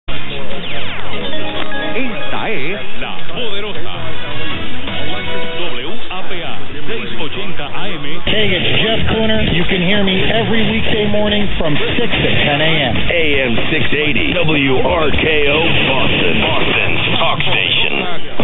Receiver: Microtelecom Perseus
Antenna: north-null cardioid-pattern SuperLoop, 15 m vertical by 20 m horizontal, base height 1.2 m
680 | PUERTO RICO | WAPA, San Juan, AUG 21 0100 - "Esta es la Poderosa .. WAPA"; in the clear during a pause in the talk on stronger WRKO.